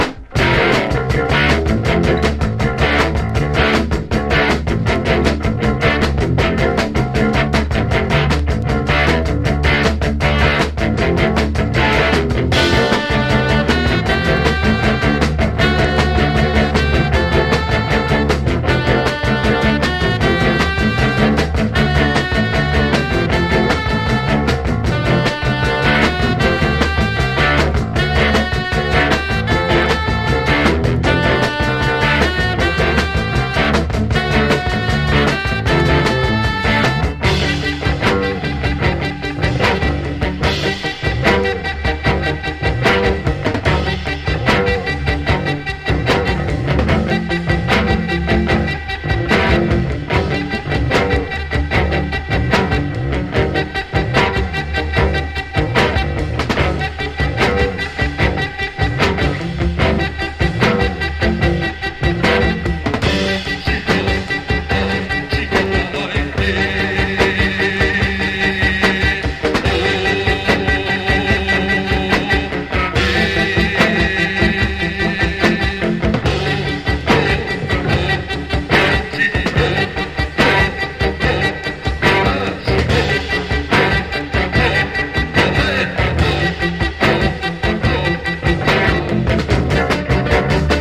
英国カルト・ポストパンク/エクスペリメンタル・バンド唯一のフル・アルバム！